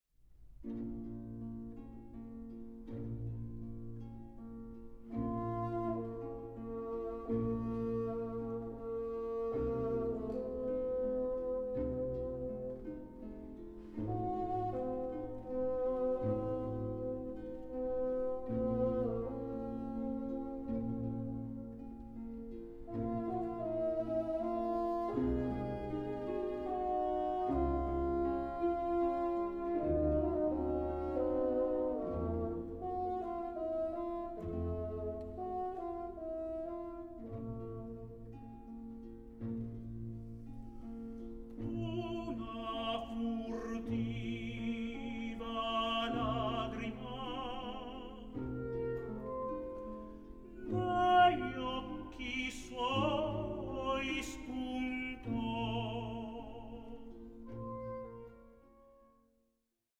TENOR ARIAS
Star tenor
period instrument playing